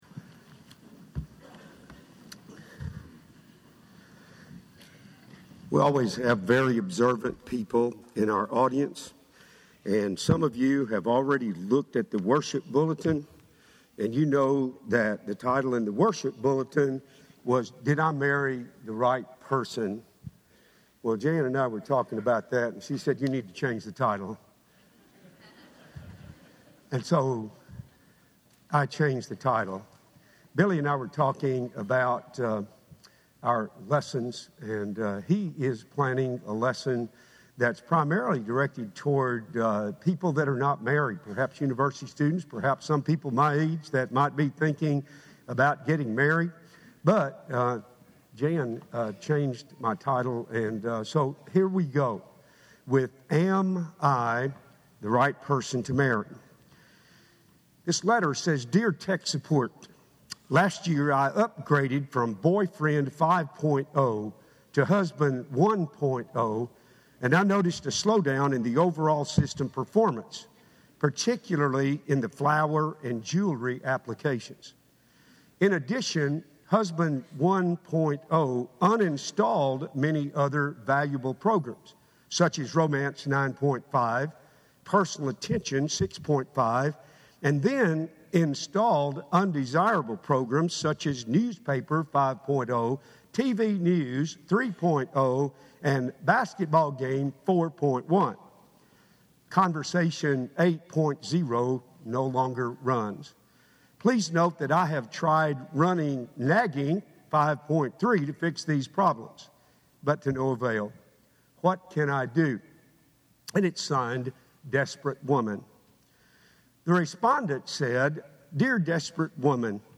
– Henderson, TN Church of Christ